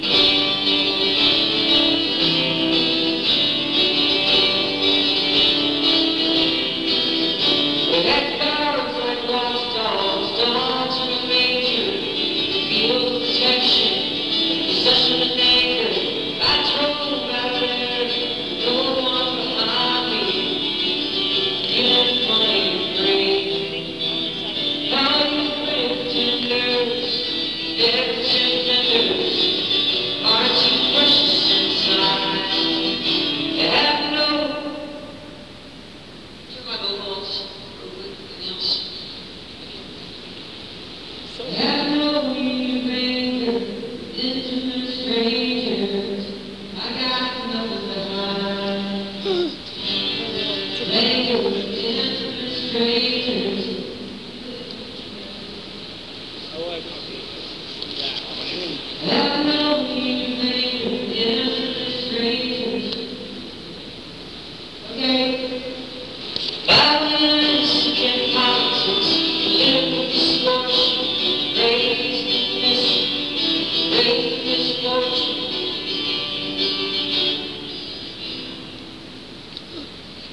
(band show)
(soundcheck)